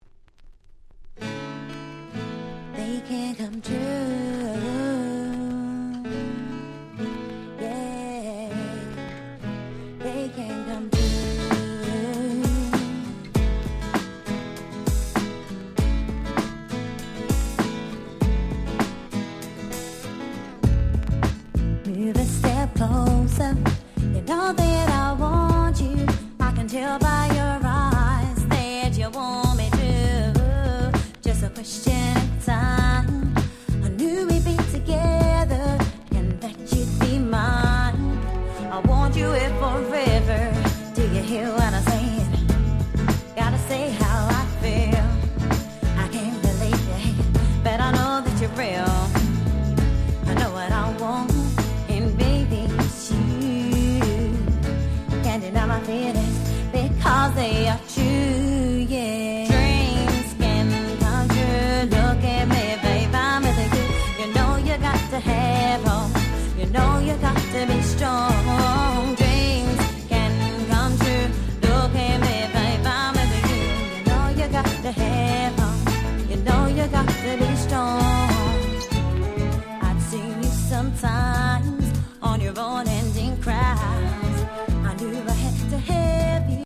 【Media】Vinyl LP